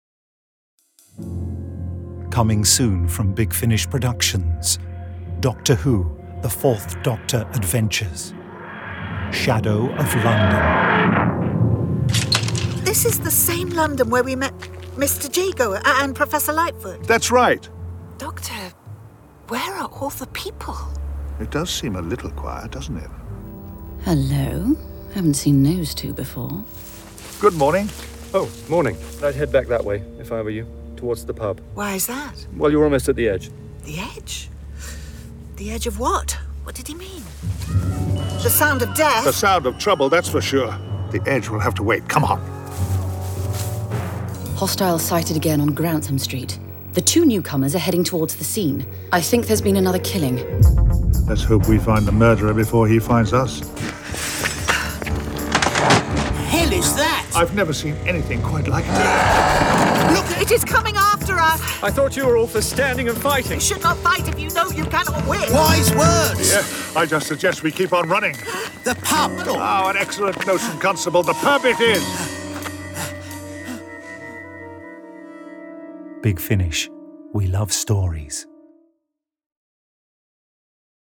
Starring Tom Baker Louise Jameson